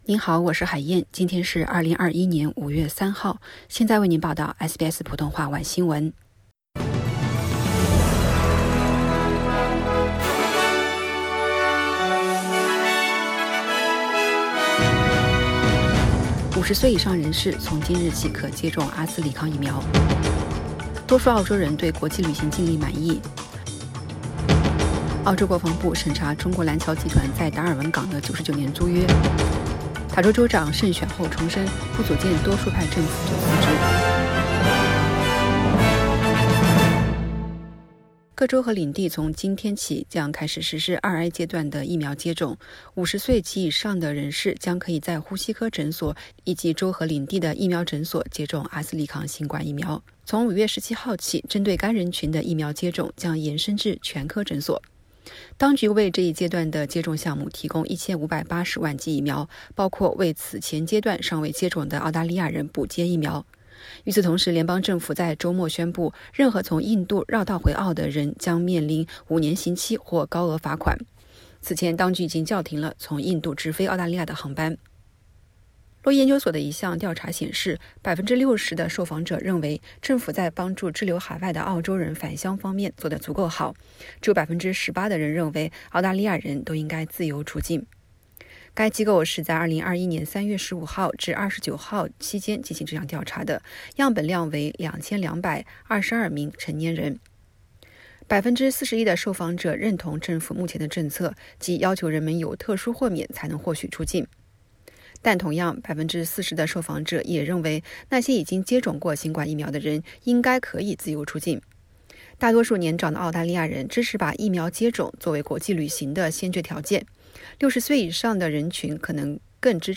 SBS晚新闻（2021年5月3日）
SBS Mandarin evening news Source: Getty Images